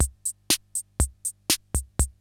CR-68 LOOPS2 4.wav